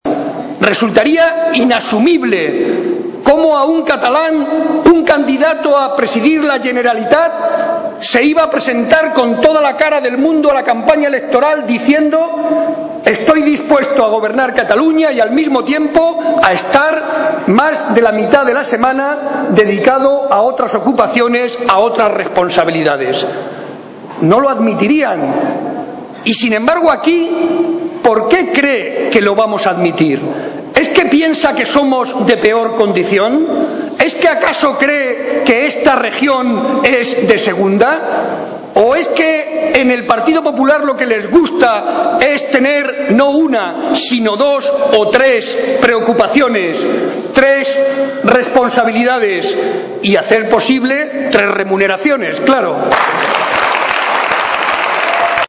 un acto en el que participaron cerca de 800 personas